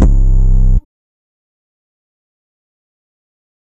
808 (Alien).wav